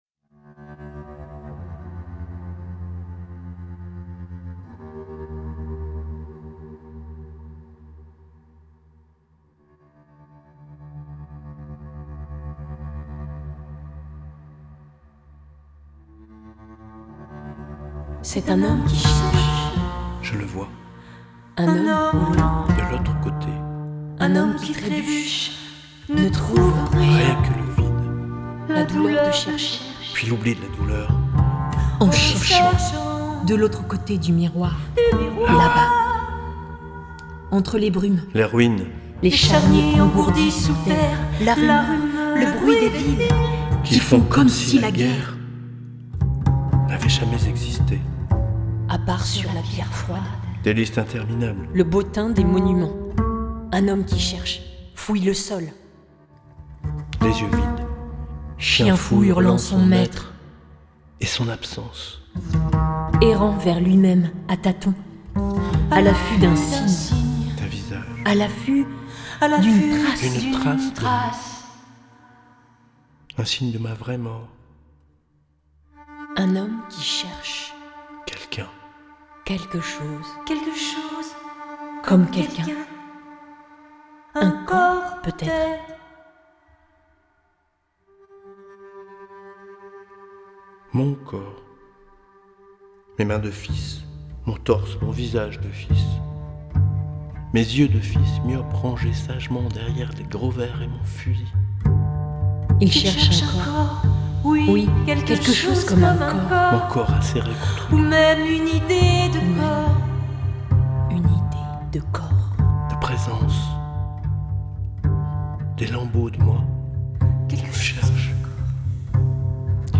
Poésie sonore